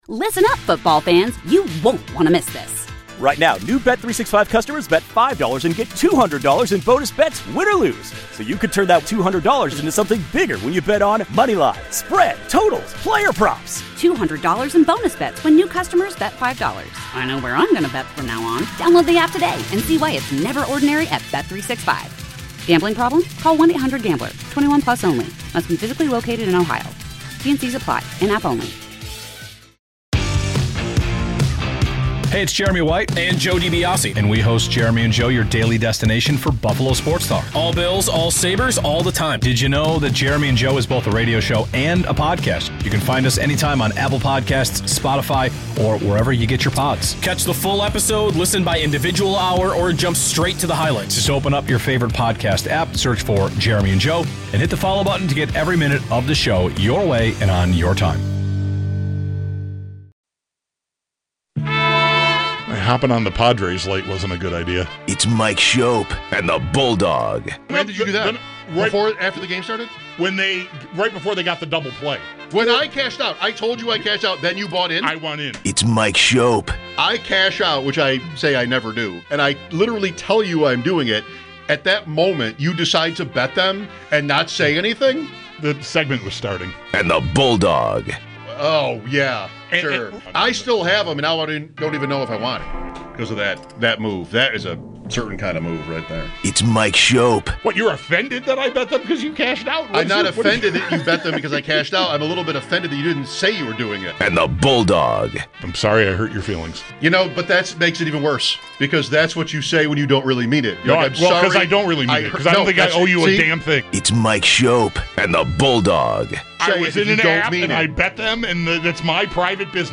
Sports talk should be entertaining and informative